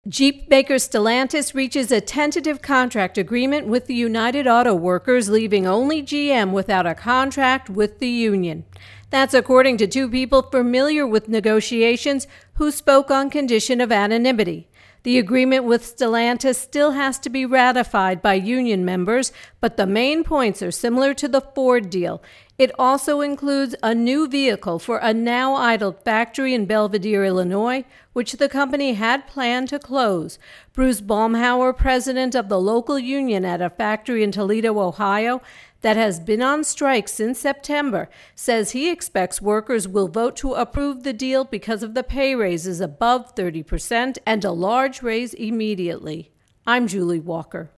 reports on Auto Workers Strikes